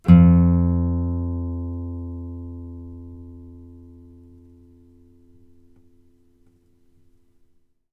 bass-11.wav